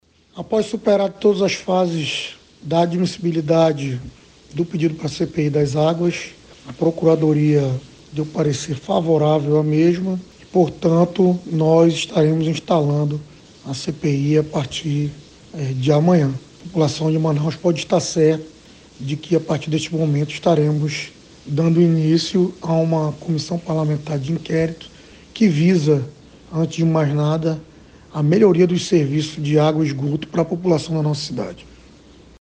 Confira na íntegra o que disse Caio André: